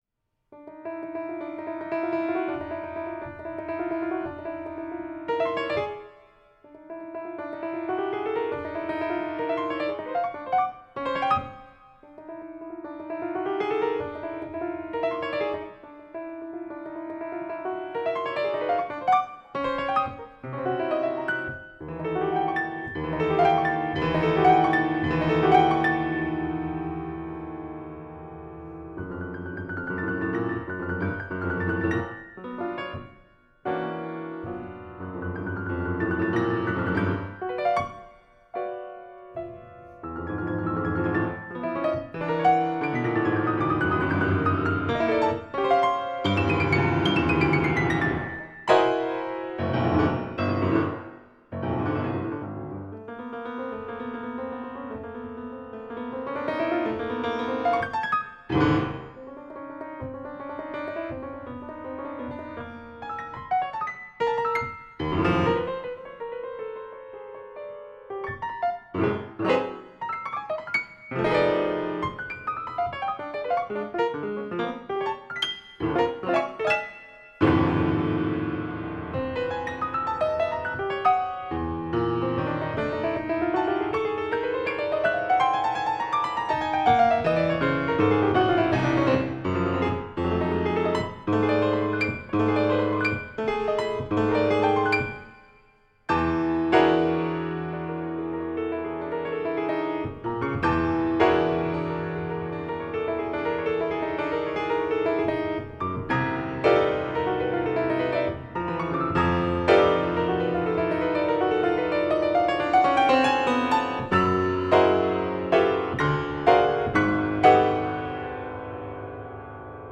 Benefit Concert
New School of Music, 25 Lowell St, Cambridge [directions]